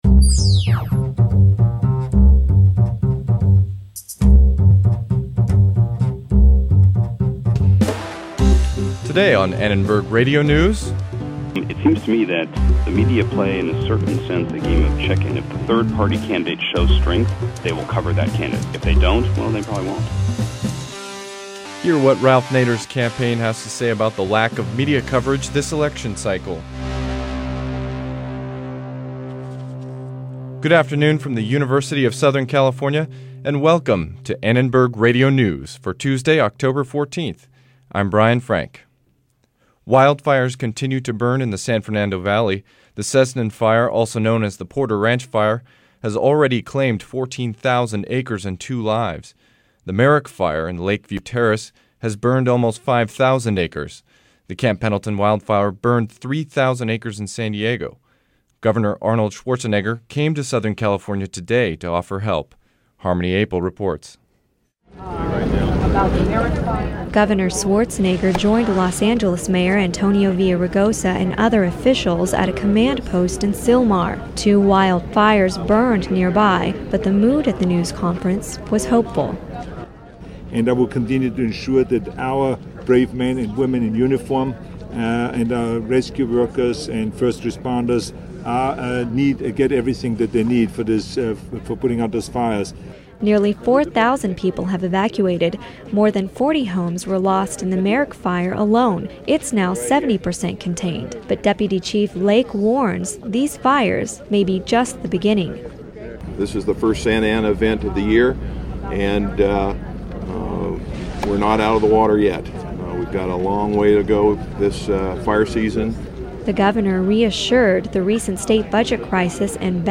Governor Schwarzenegger held a news conference to reaffirm the state's commitment to fighting the San Fernando Valley wildfires. We hear from the governor and talk to fire officials about the disaster.